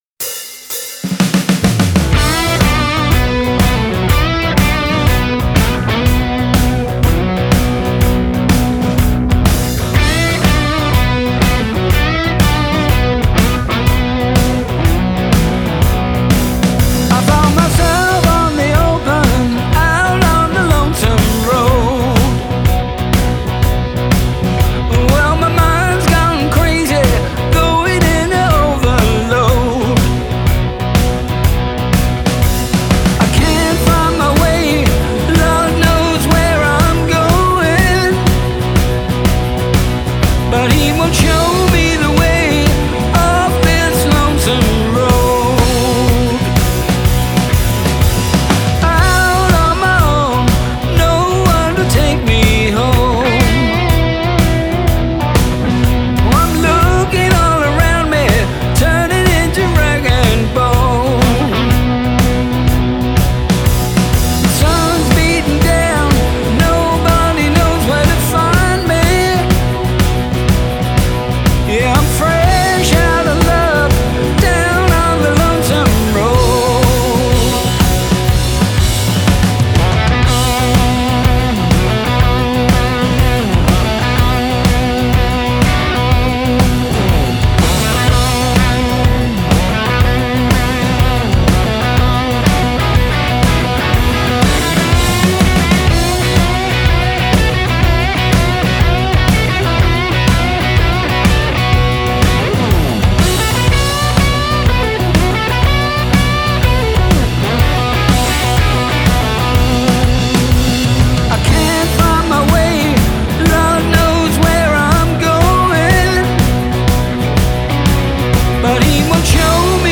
рок-музыка, рок-н-ролл